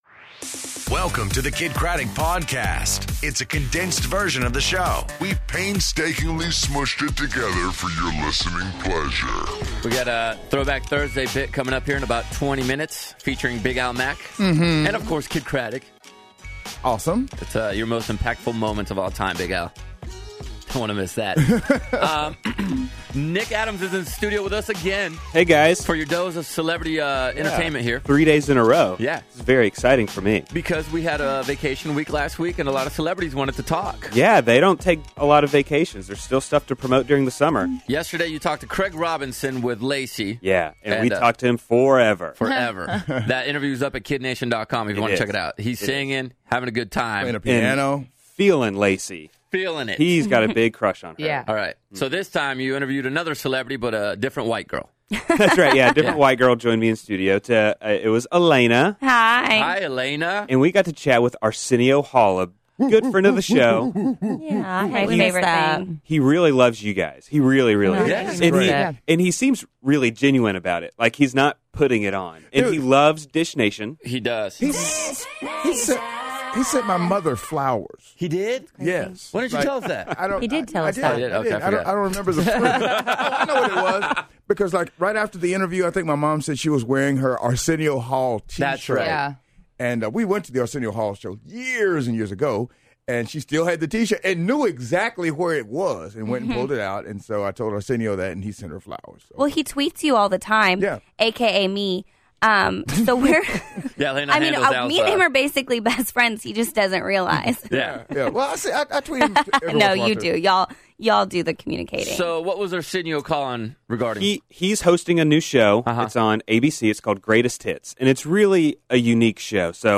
First World Problems, Aresenio Hall On The Phone, And In Love With Who?